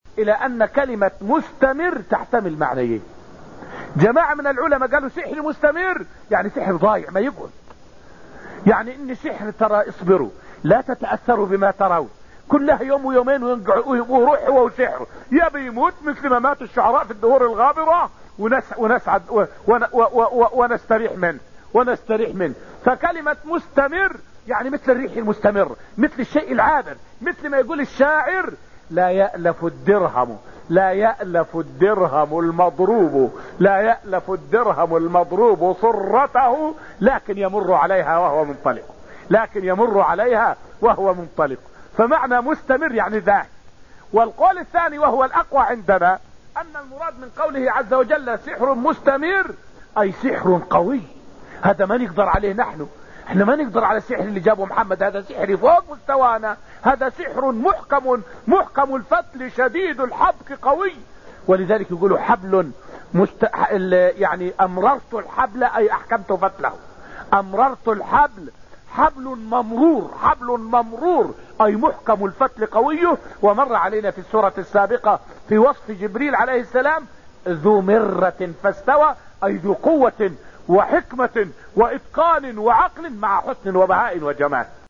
فائدة من الدرس الثاني عشر من دروس تفسير سورة القمر والتي ألقيت في المسجد النبوي الشريف حول مخالفة بعض المسلمين في غزوة أحد وما جرى وقتها للمسلمين.